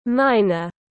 Thợ mỏ tiếng anh gọi là miner, phiên âm tiếng anh đọc là /ˈmaɪ.nər/.
Miner /ˈmaɪ.nər/
miner.mp3